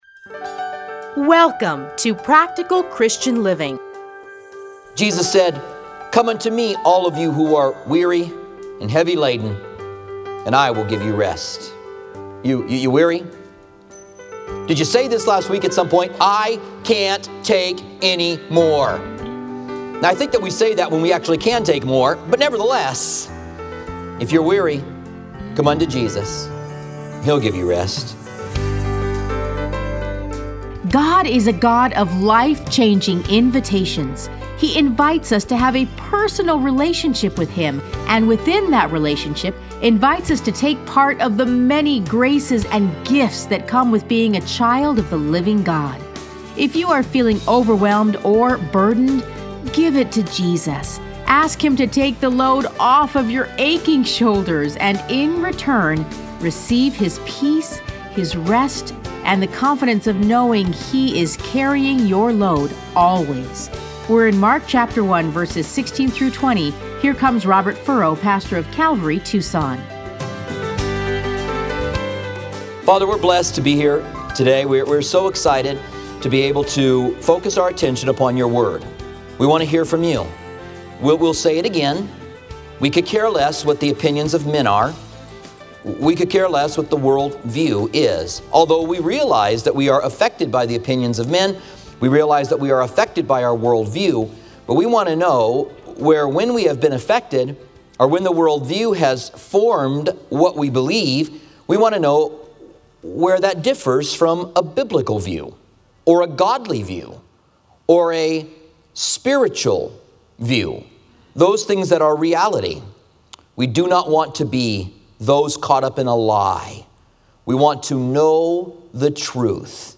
Listen to a teaching from Mark 1:16-20.